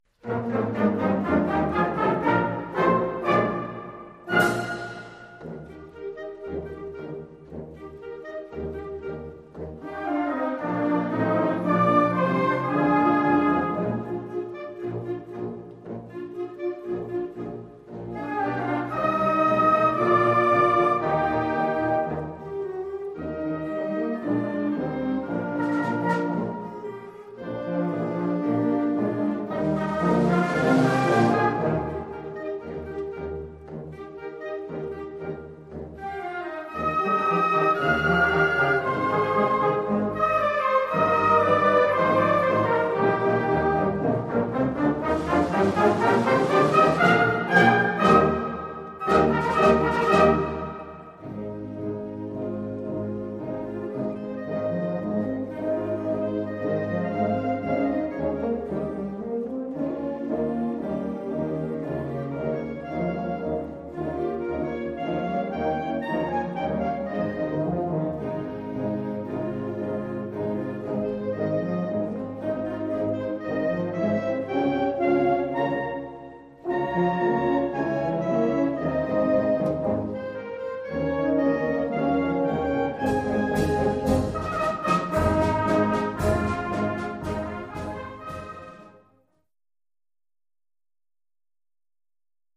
Collection : Harmonie (Marches)
Marche de concert pour
harmonie ou fanfare,